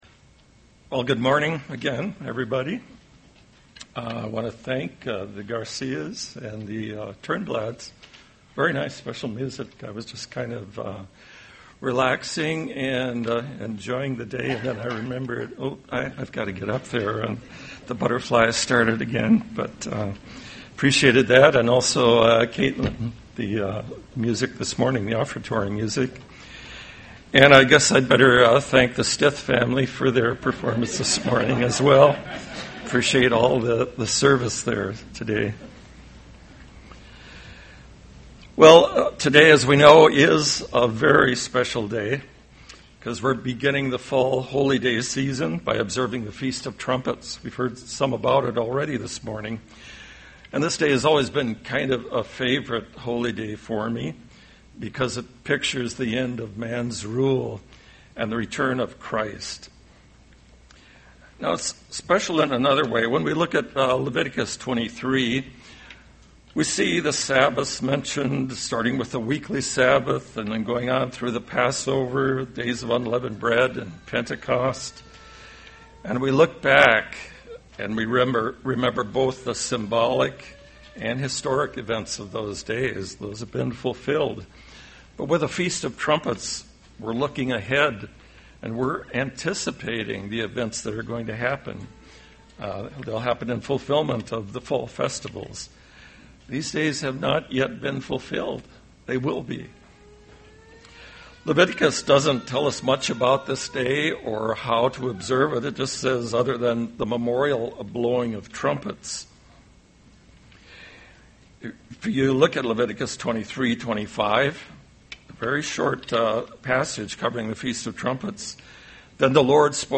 Given in Twin Cities, MN
UCG Sermon Feast of Trumpets spiritual temple of God temple of God appearance Studying the bible?